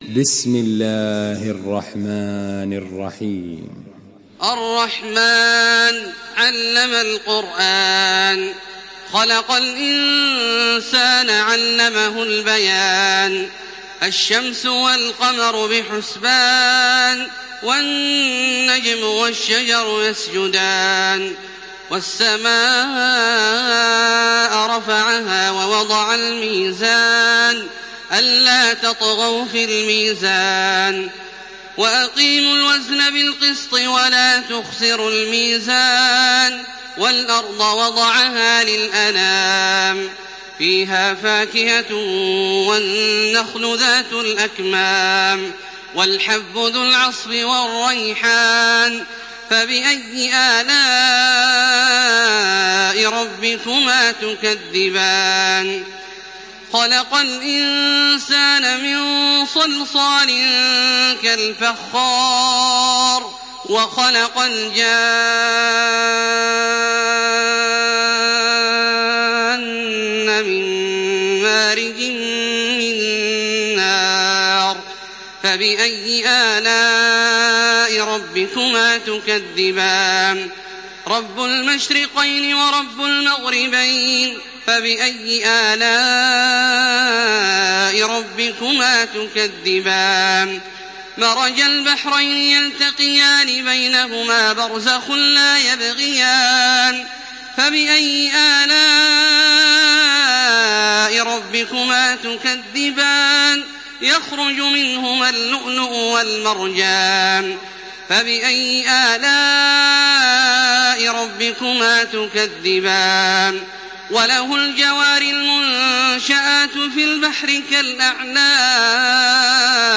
سورة الرحمن MP3 بصوت تراويح الحرم المكي 1428 برواية حفص عن عاصم، استمع وحمّل التلاوة كاملة بصيغة MP3 عبر روابط مباشرة وسريعة على الجوال، مع إمكانية التحميل بجودات متعددة.
تحميل سورة الرحمن بصوت تراويح الحرم المكي 1428
مرتل